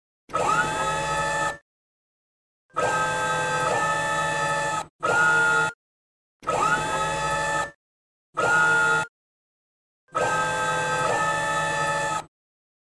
Tiếng Robot hoạt động, di chuyển
Âm thanh máy móc phát ra do con người điều khiển robot đang làm một việc gì đó dùng trong các bộ phim khoa học viễn tưởng..
Chủ đề: Khoa Học Viễn Tưởng tiếng máy móc tiếng robot
Đây là tiếng robot hoạt động, di chuyển.
tieng-robot-hoat-dong-di-chuyen-www_tiengdong_com.mp3